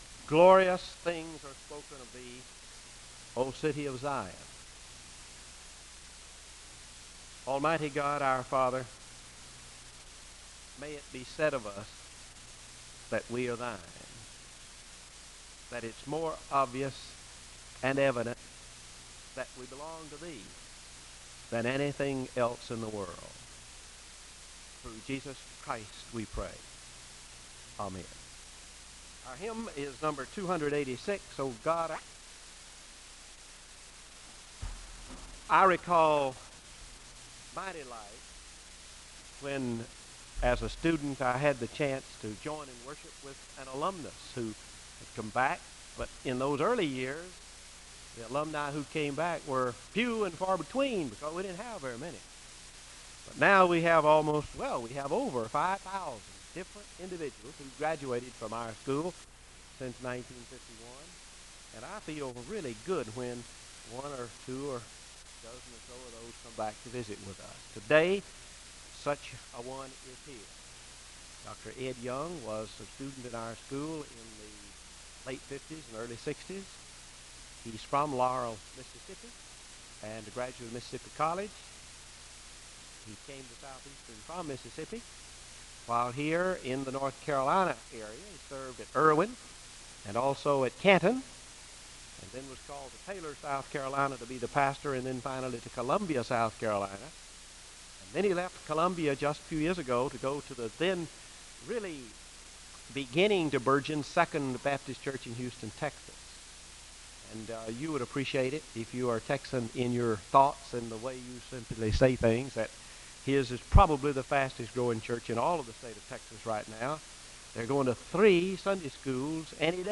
Audio quality is low.
The choir sings a song of worship (04:16-08:25).